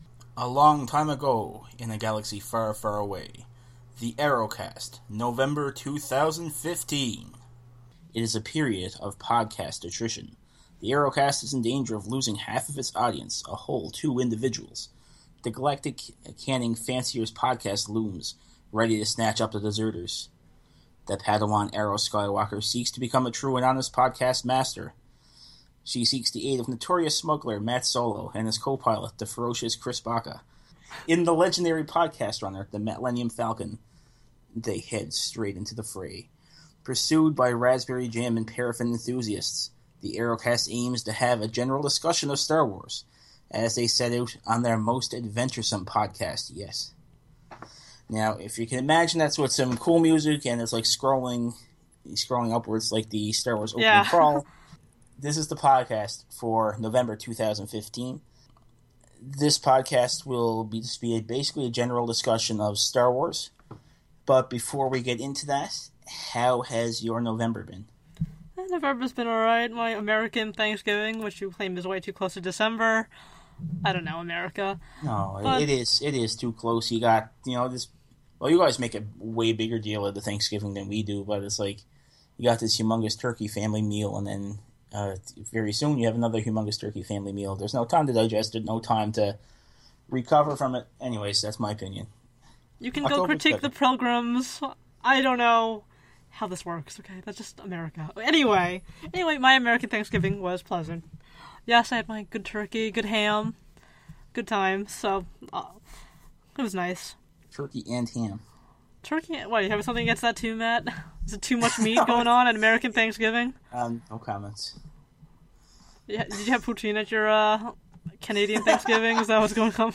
The AGROCAST for November 2015 is a general discussion of our time with Star Wars.
The intermissions are from classic Kenner tv spots.